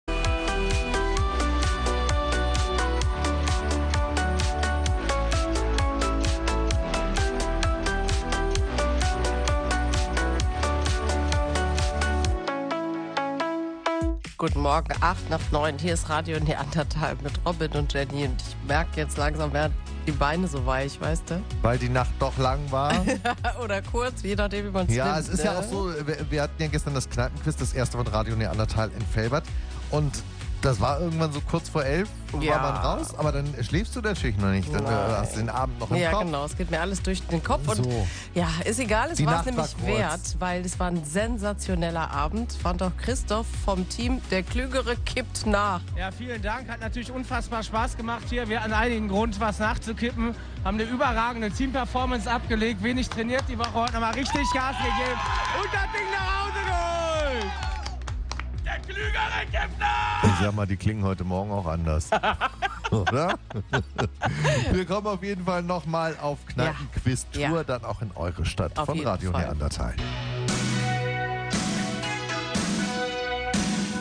Das erste "Radio Neandertal Kneipenquiz" im Restaurant "Da Vinci" in Velbert. Dreizehn Teams hatten jede Menge Spass beim Rätseln, Quizzen und Musiktitel über Kreuz erkennen.